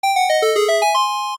compile success.ogg